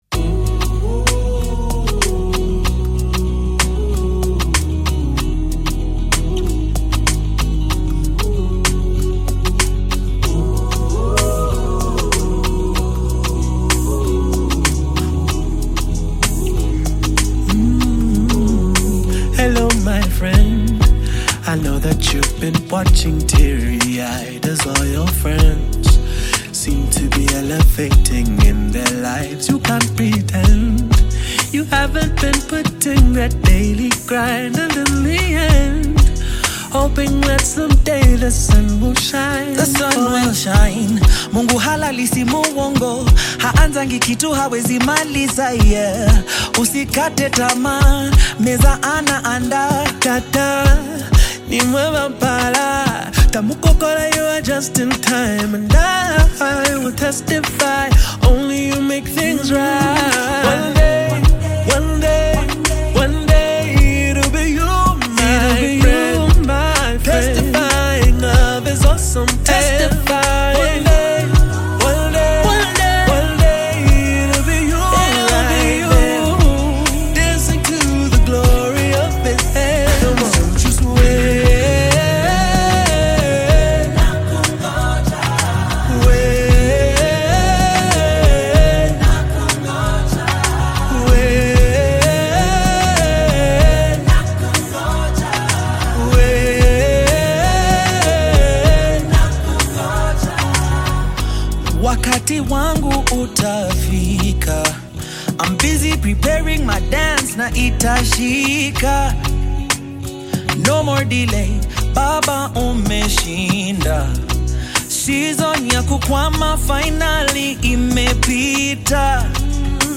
Kenyan gospel singer and songwriter
gospel song